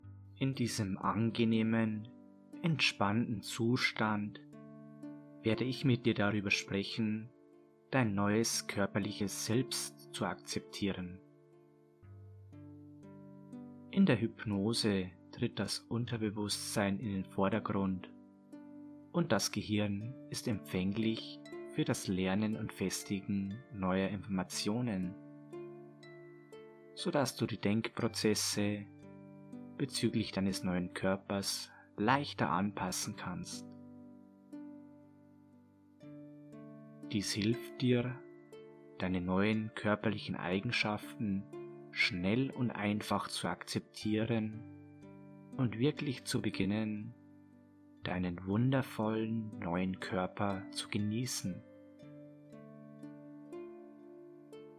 Nach einer herrlich entspannenden Einleitung wirst Du in eine tiefe Trance geführt, wo direkt mit Deinem Unterbewusstsein kommuniziert und gearbeitet werden kann.
Q7003a-Transgender-Koerperakzeptanz-und-Selbstbewusstsein-Hauptsitzung-Hoerprobe.mp3